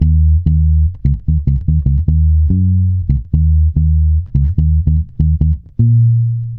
-MM RAGGA D#.wav